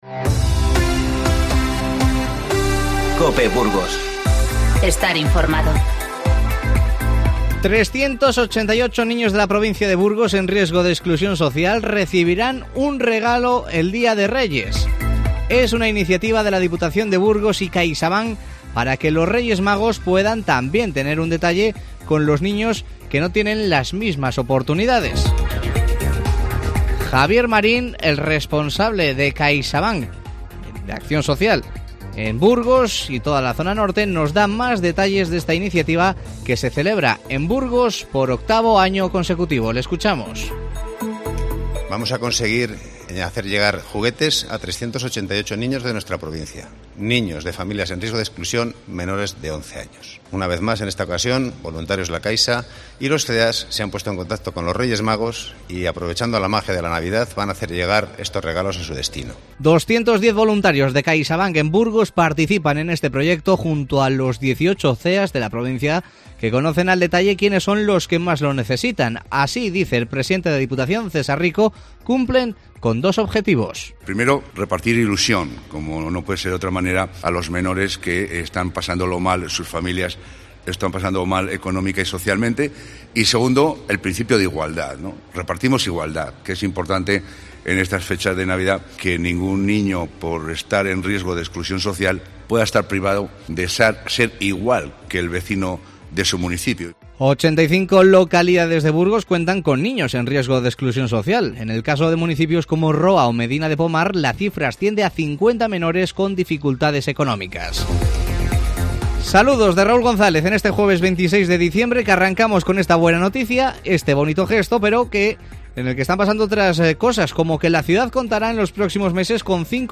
INFORMATIVO Mediodía 26-12-19